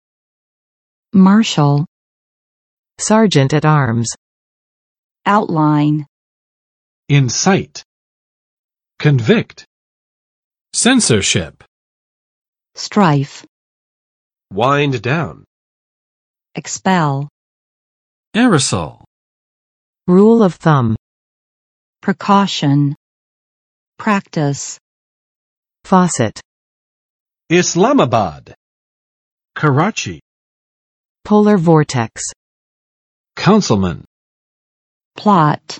[ˋmɑrʃəl] n.【美】联邦法院执行官; 法警
marshal.mp3